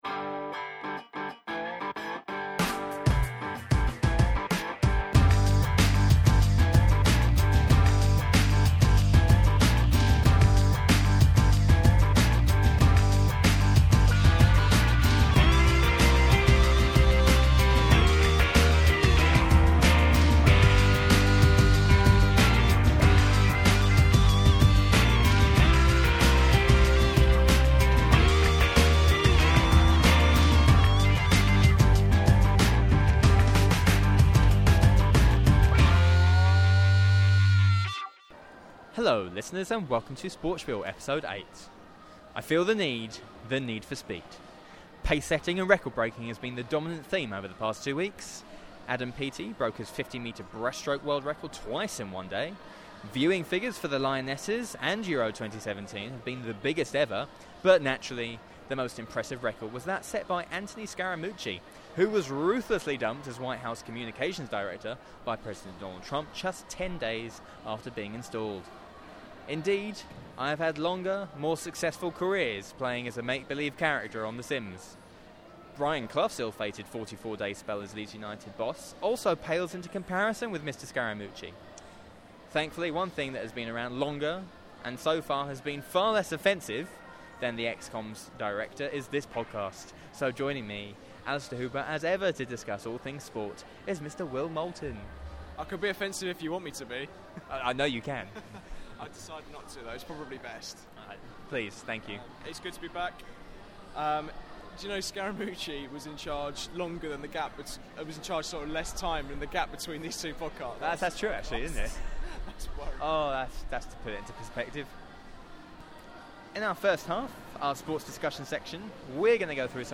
Our feature interview segment in this episode is with 2014 Rugby World Cup-winning star Marlie Packer.